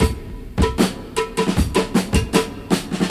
• 78 Bpm High Quality Breakbeat C Key.wav
Free drum groove - kick tuned to the C note. Loudest frequency: 1454Hz
78-bpm-high-quality-breakbeat-c-key-xG6.wav